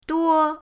duo1.mp3